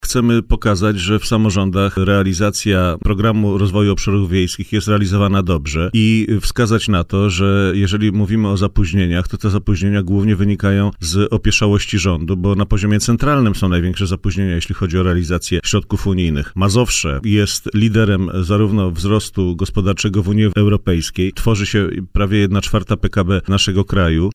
– Chcemy pokazać, że samorządy dobrze realizują program rozwoju wsi – stwierdził poseł PSL Piotr Zgorzelski w poranku „Siódma9” na antenie Radia Warszawa.